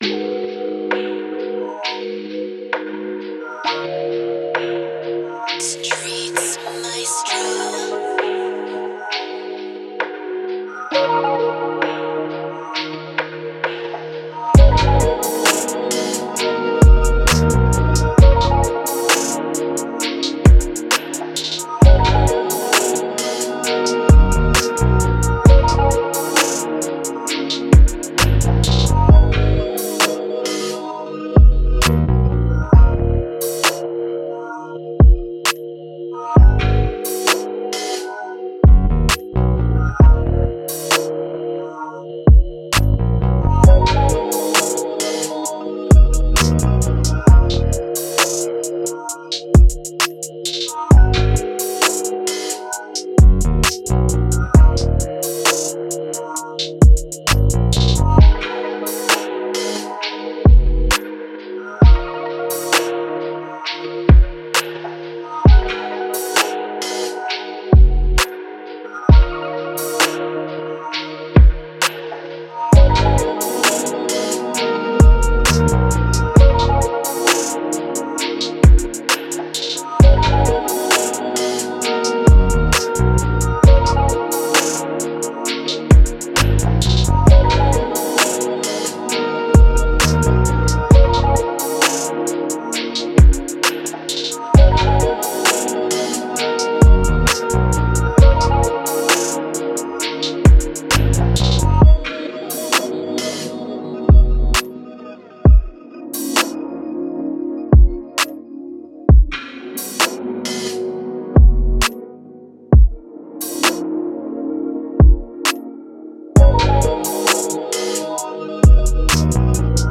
Moods: intimate, laid back, smooth
Genre: R&B
Tempo: 132
BPM 130
Moods: intimate, mellow, smooth